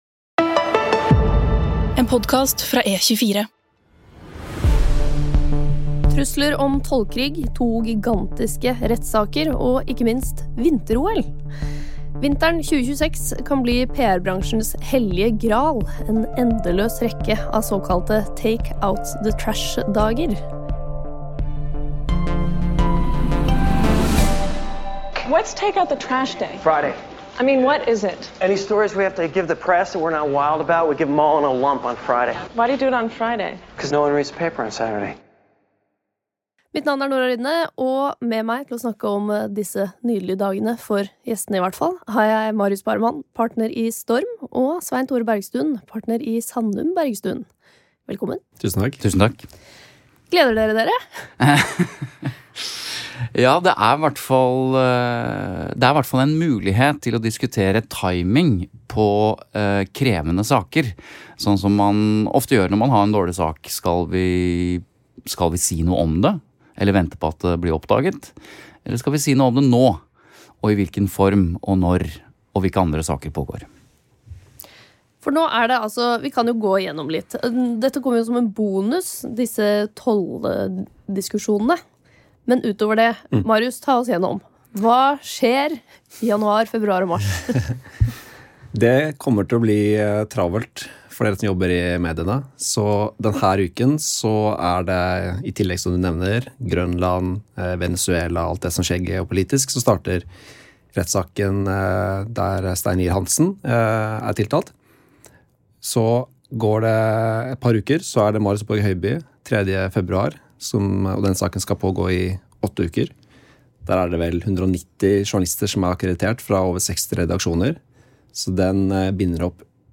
Episoden inneholder et lydklipp fra tv-serien West Wing (S1,E13), produsert av John Wells Production og Warner Bros. Television.